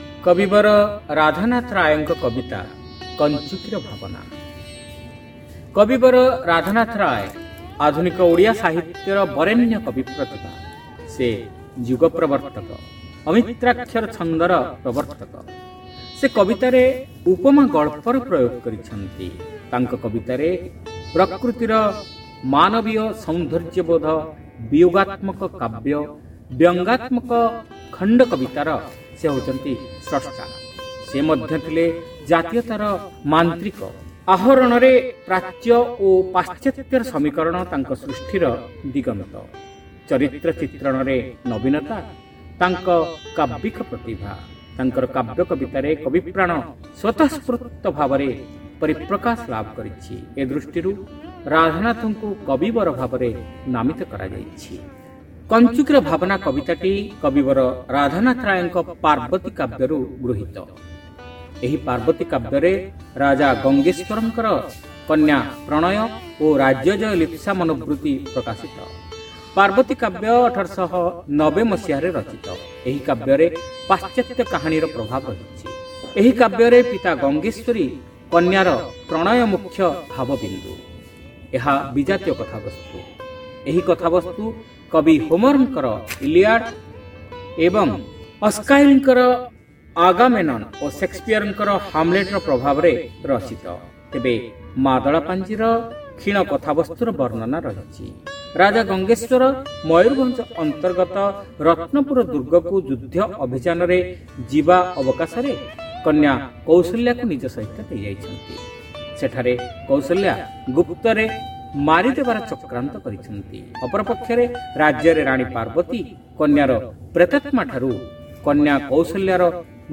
Audio Poems : Kanchuti Ra Bhabana